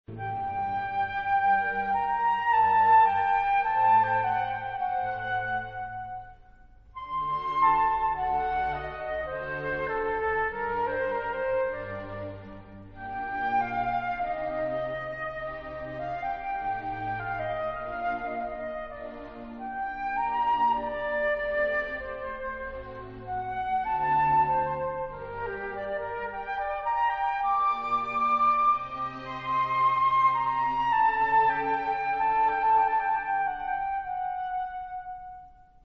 flute
"4 concertos"